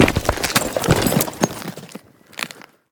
5caee9fba5 Divergent / mods / Ledge Climbing Sounds Redone / gamedata / sounds / ledge_grabbing / Vaulting / Medium / medium3.ogg 44 KiB (Stored with Git LFS) Raw History